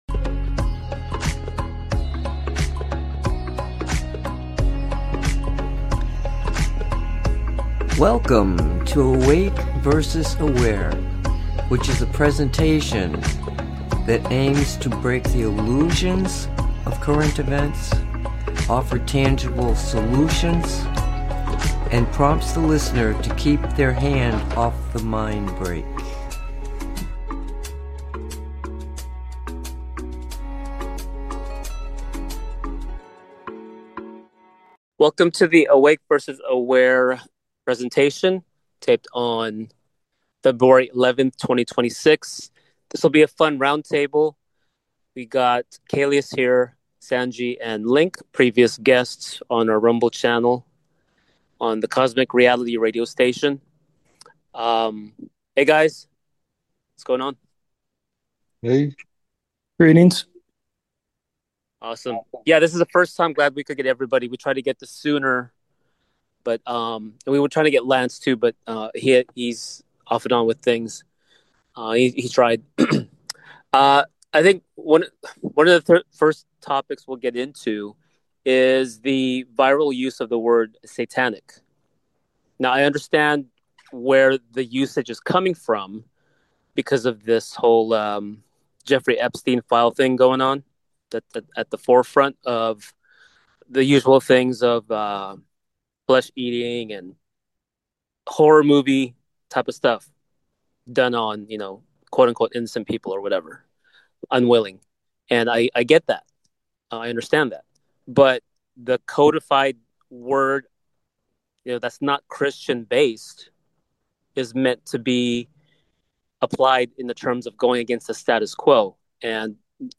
Roundtable